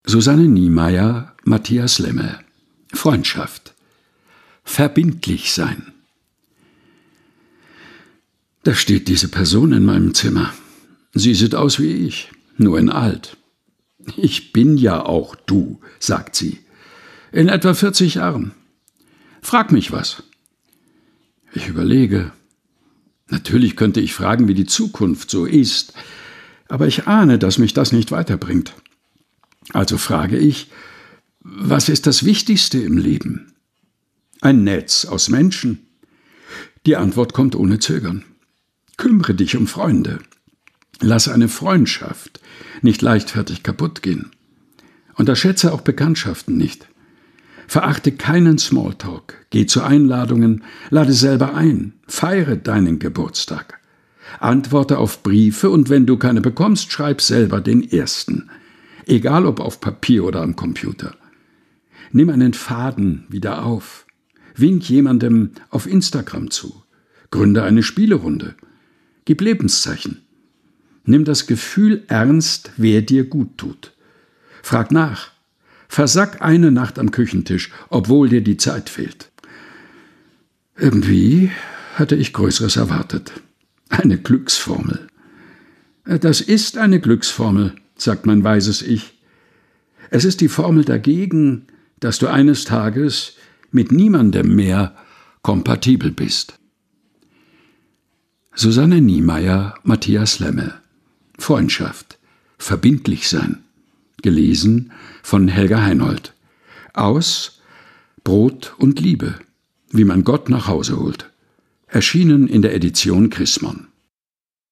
im Dachkammerstudio vorgelesen
Texte zum Mutmachen und Nachdenken - vorgelesen